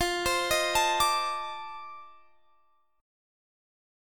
F13 Chord
Listen to F13 strummed